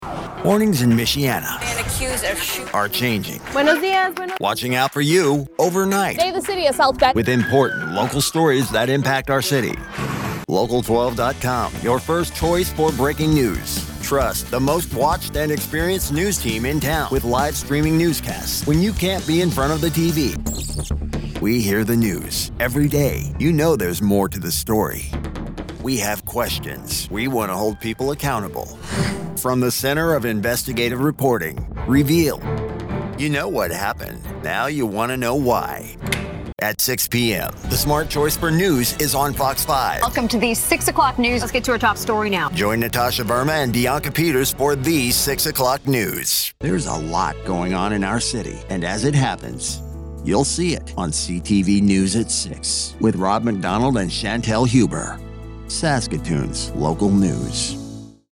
Male Voice Actor | Confident, Trustworthy, Conversational | Commercial & Corporate Specialist
CONFIDENT, AUTHORITATIVE TV AFFILIATE PROMO
I work from a home studio, but am open to other options of recording your project if time and reasonable arrangements are agreed upon.